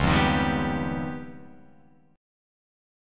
C#9b5 chord